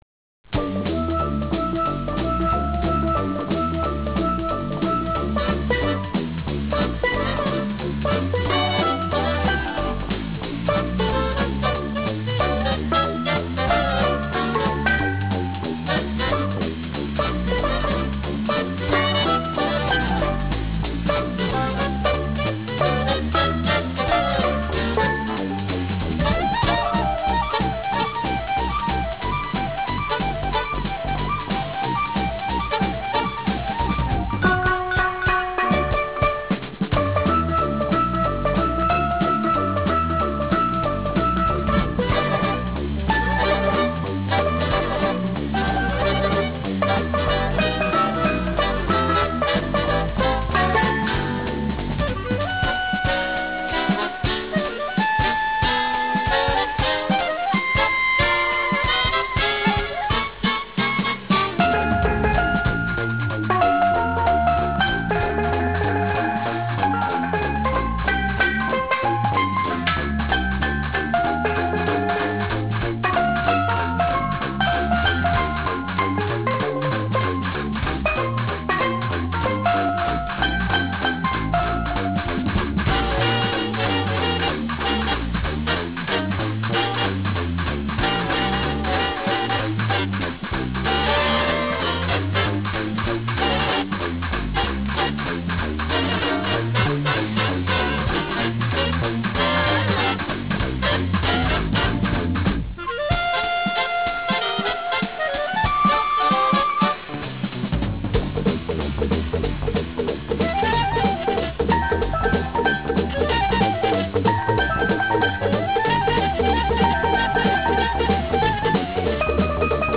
Kloo Horn and Gasan String Drum
Fanfar
Bandfill with Horn Bells